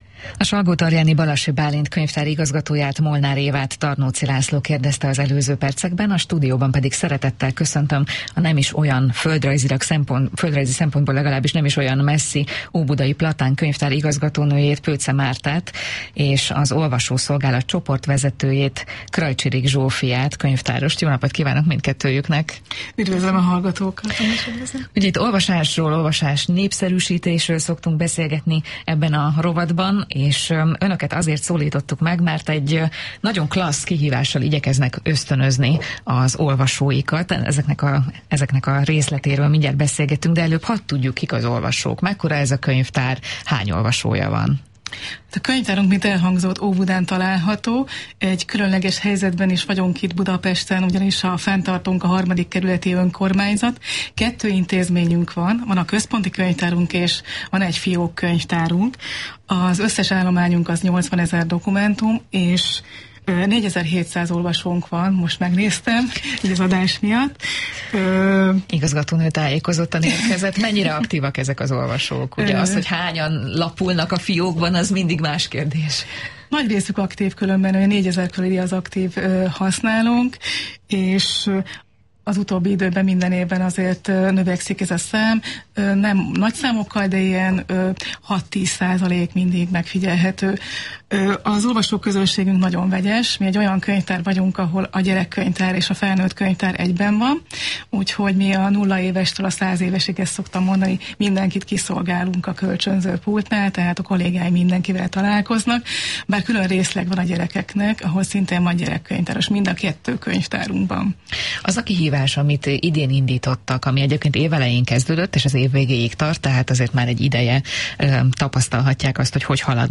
Hallgasd meg a Kossuth Rádió Délelőtt című műsorában elhangzott beszélgetést Olvasói Kihívásunkról, és inspirálódj munkatársaink lelkesedéséből! 2023. február 1-től indult a felnőtteknek szóló kihívás, de a gyerekeknek is van lehetőségük csatlakozni mindkét könyvtárunkban.
Kossuth-Radio-Obudai-Platan-Konyvtar.mp3